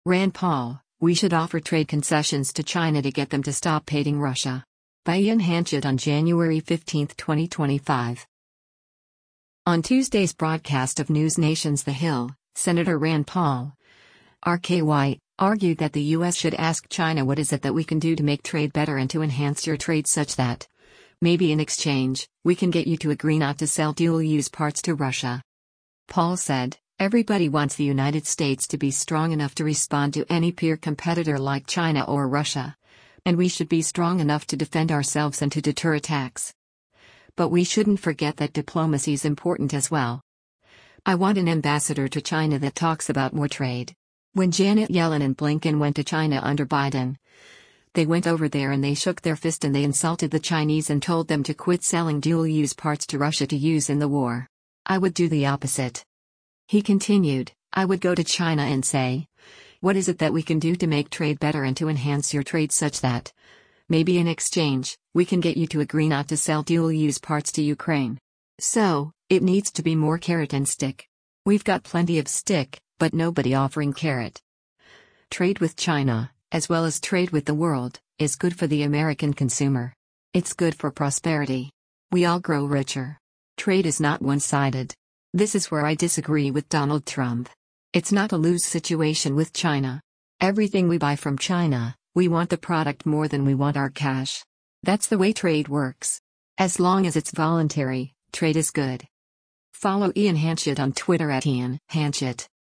On Tuesday’s broadcast of NewsNation’s “The Hill,” Sen. Rand Paul (R-KY) argued that the U.S. should ask China “what is it that we can do to make trade better and to enhance your trade such that, maybe in exchange, we can get you to agree not to sell dual-use parts” to Russia.